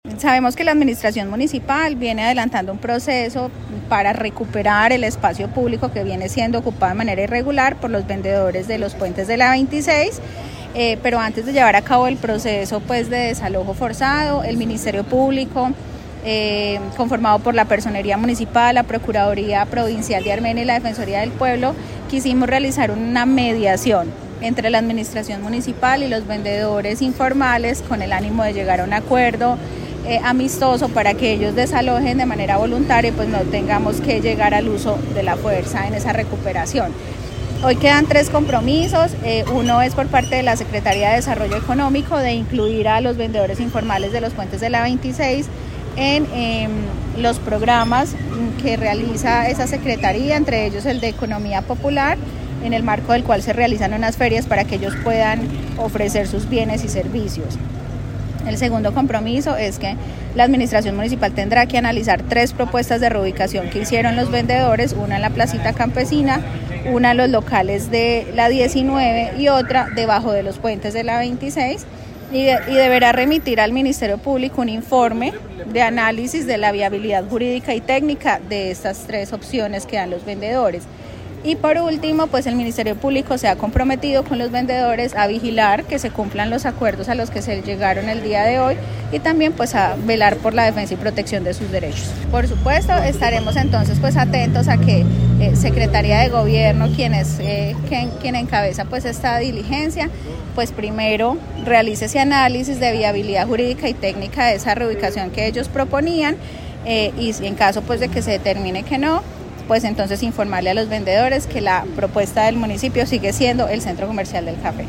Personera de Armenia